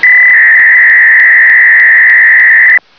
Packet radio : transmission par paquets HF